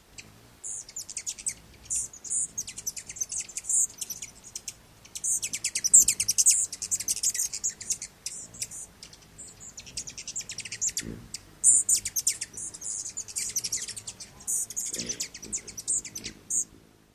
棕煌蜂鸟叫声